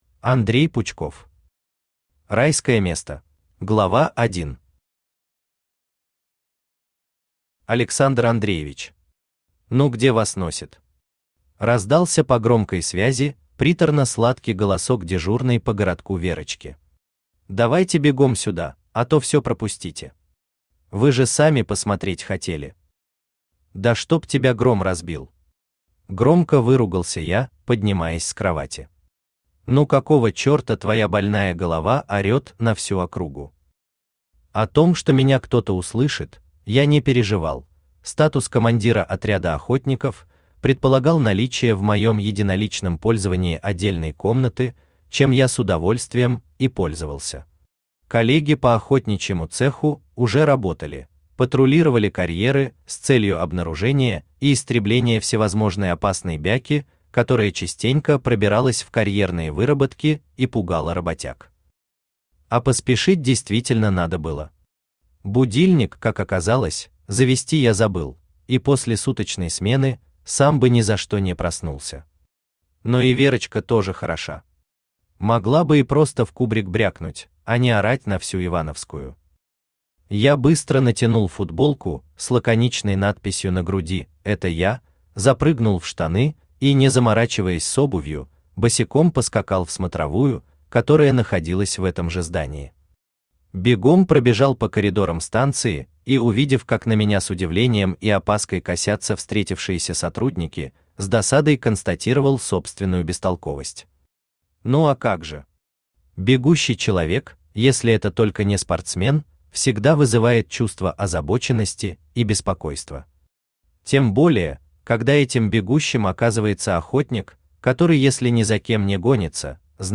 Аудиокнига Райское место | Библиотека аудиокниг
Aудиокнига Райское место Автор Андрей Викторович Пучков Читает аудиокнигу Авточтец ЛитРес.